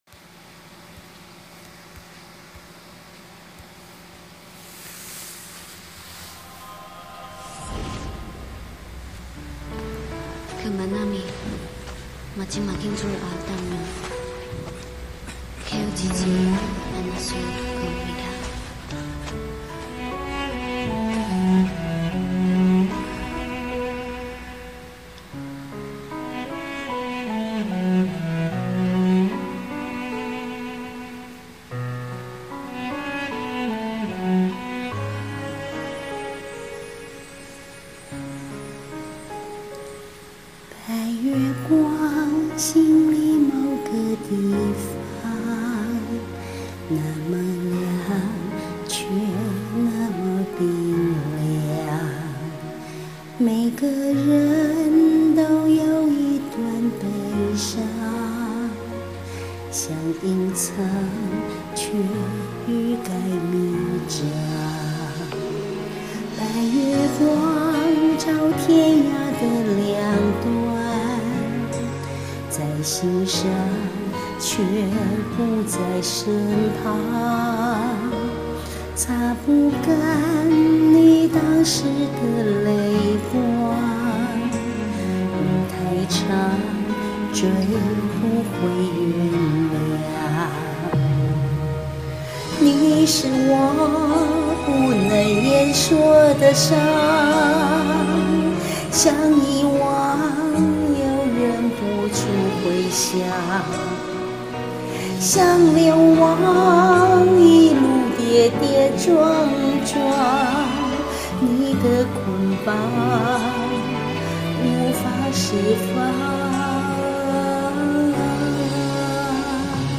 淡淡的感傷與懷念
只好在家拿著手機玩錄音APP囉~